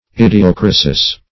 idiocrasis - definition of idiocrasis - synonyms, pronunciation, spelling from Free Dictionary Search Result for " idiocrasis" : The Collaborative International Dictionary of English v.0.48: Idiocrasis \Id`i*o*cra"sis\, n. [NL.]